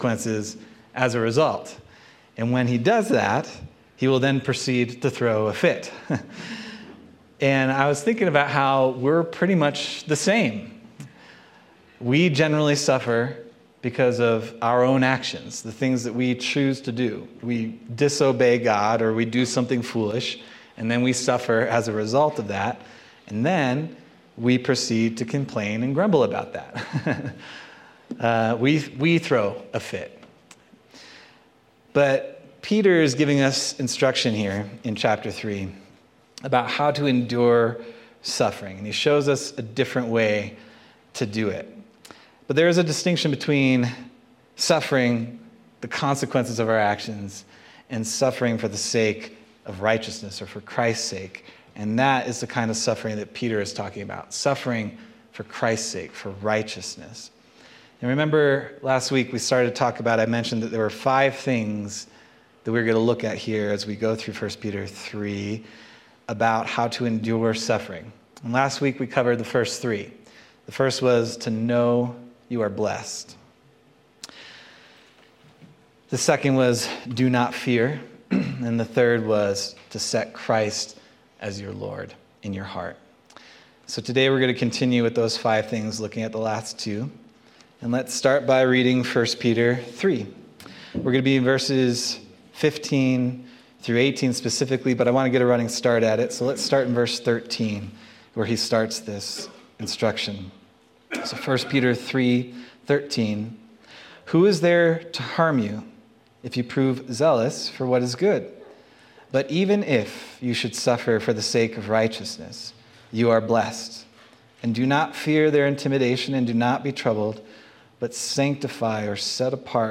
May 25th, 2025 Sermon